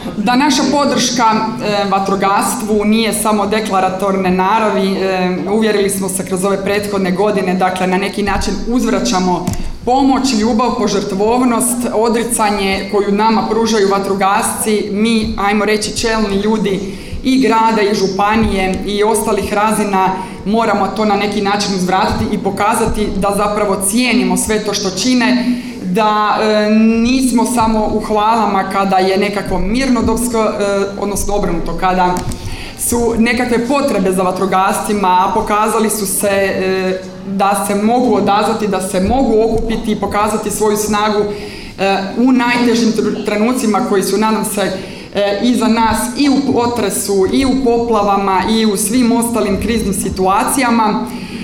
Svečanim presijecanjem vrpce otvorene su novoobnovljene prostorije DVD-a Petrinja.
Gradonačelnica Petrinje i saborska zastupnica Magdalena Komes podsjetila je kako su upravo prostore DVD-a Petrinja, nakon potresa, koristile brojne udruge, kao i Grad Petrinja za potrebe održavanja sjednica Gradskog vijeća.